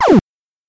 枪声 " 枪声 1
描述：枪声的混合声音
Tag: 激光 混音 拍摄